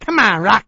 l_comeonROCK.wav